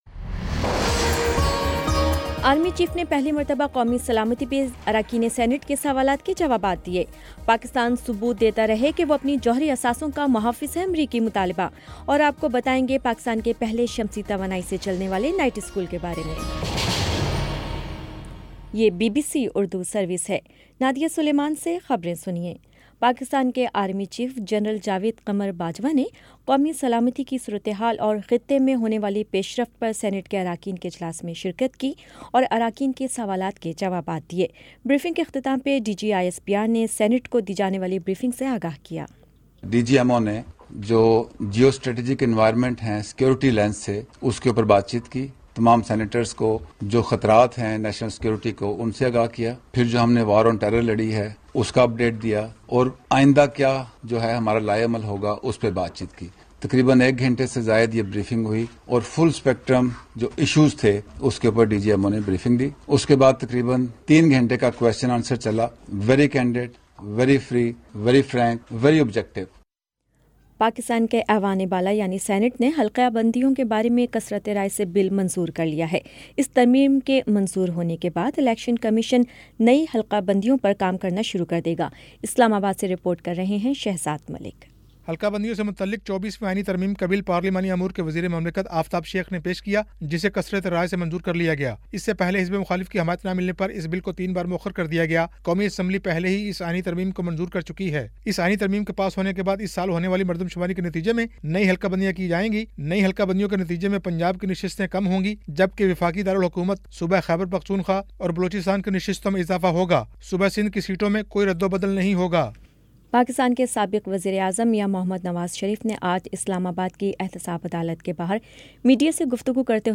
دسمبر 19 : شام چھ بجے کا نیوز بُلیٹن